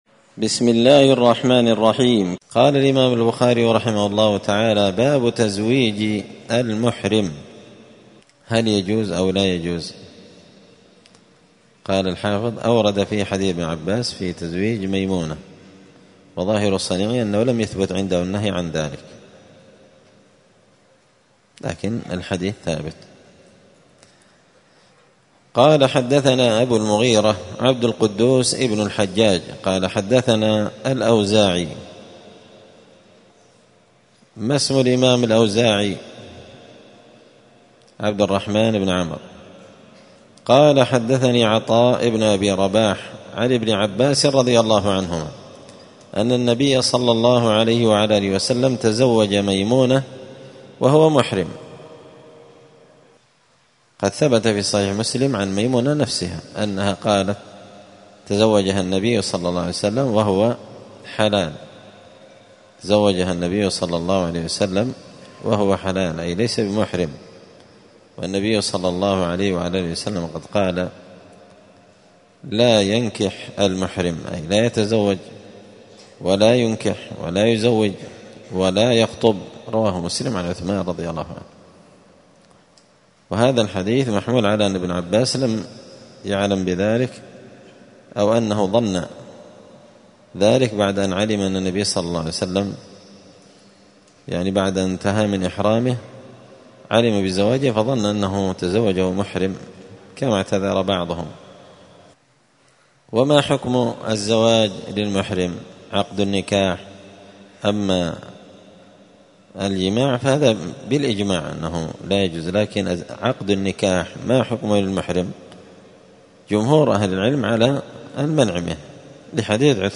كتاب جزاء الصيد من شرح صحيح البخاري- الدرس 12 بَابٌ: تَزْوِيجِ الْمُحْرِمِ.